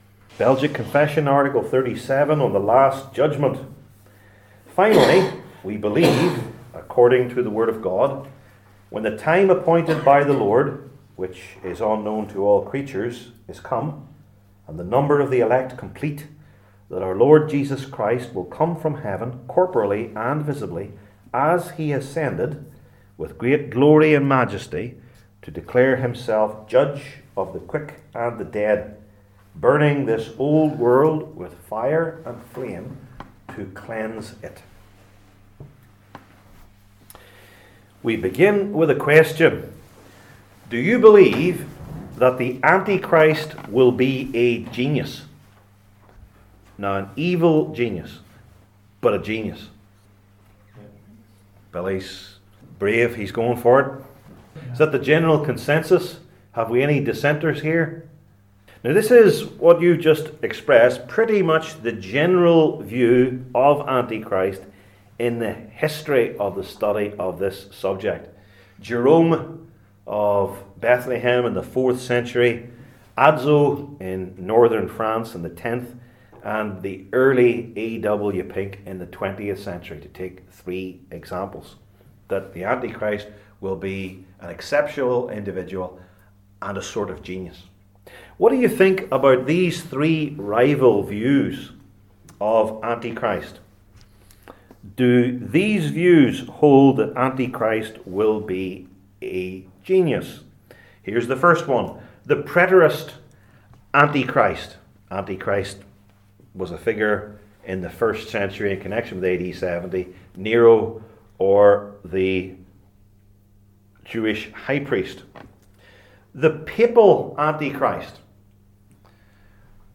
Passage: Daniel 7:1-12, 19-26 Service Type: Belgic Confession Classes